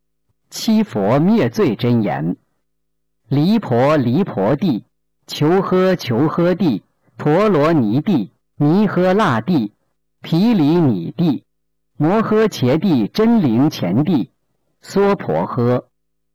006《七佛灭罪真言》男声
目录：佛教经文